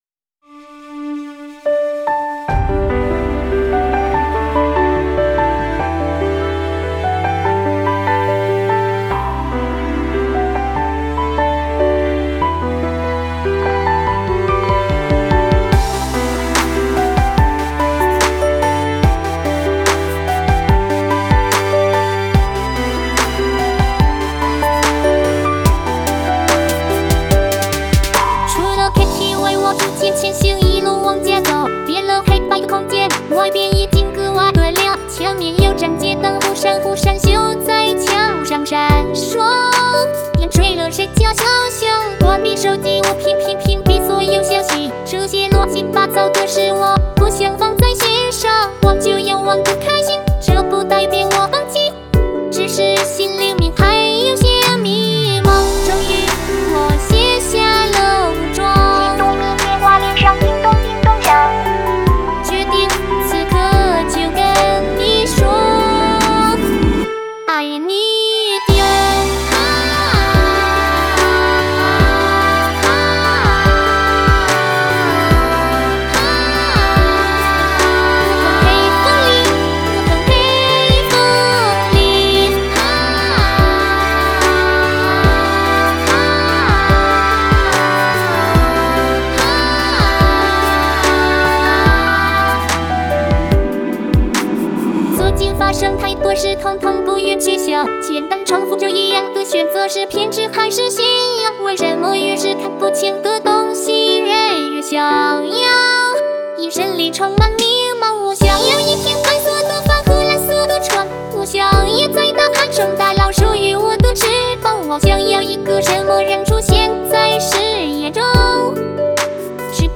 绝对称得上是抒情版，高潮部分深情到骨子里了